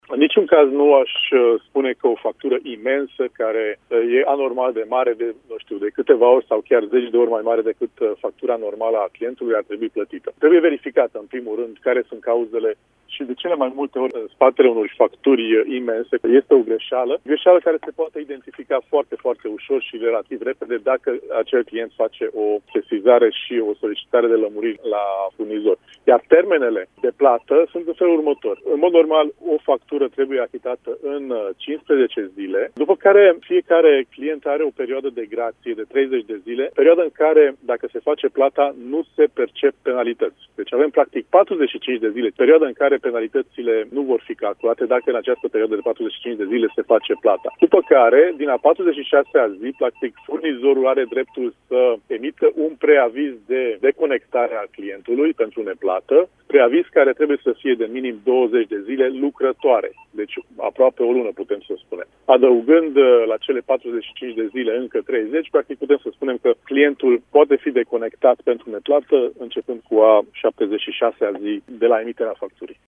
Vicepreședintele ANRE, Zoltan Nagy-Bege, cu explicații despre plata facturilor: